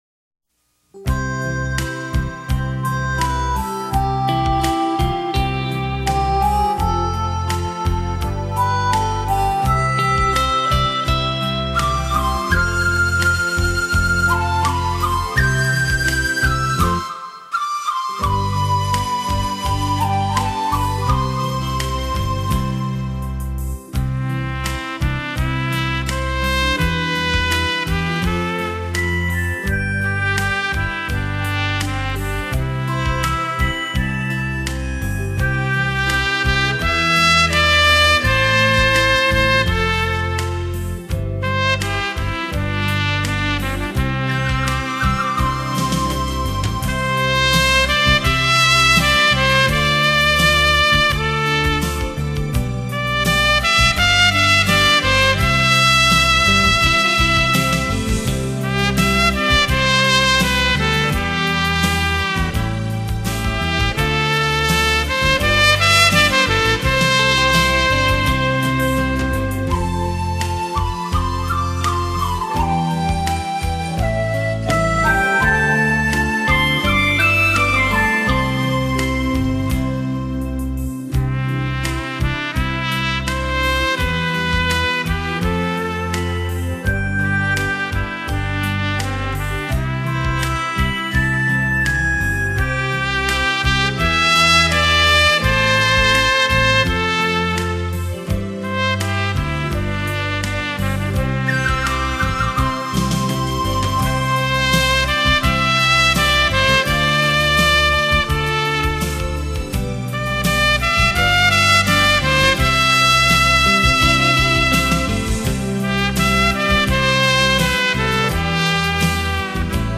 一般张扬，但酝酿着无法内敛的高贵本色，鲜亮嘹亮，灿
烂辉煌的小号吹奏，一如橙色给人心暖融融的感觉　　。